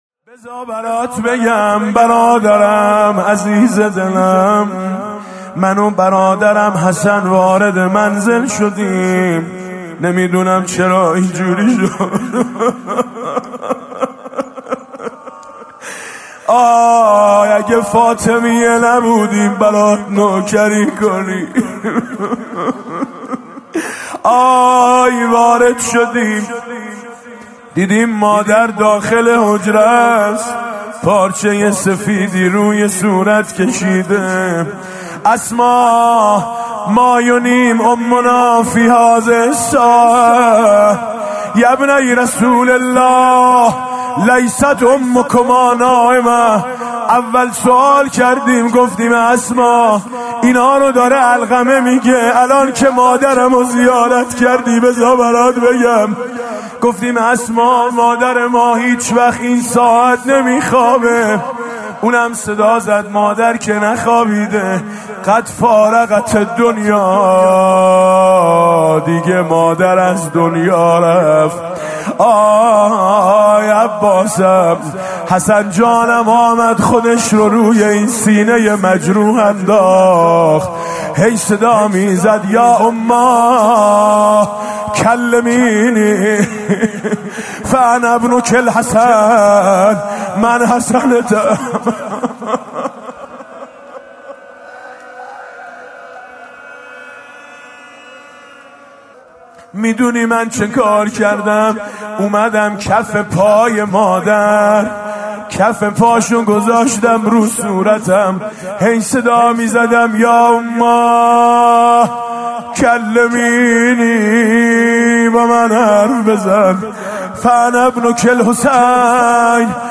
مداحی شب عاشورای محرم 1399 با نوای میثم مطیعی
زمزمه: ممنون دستاتم علمدار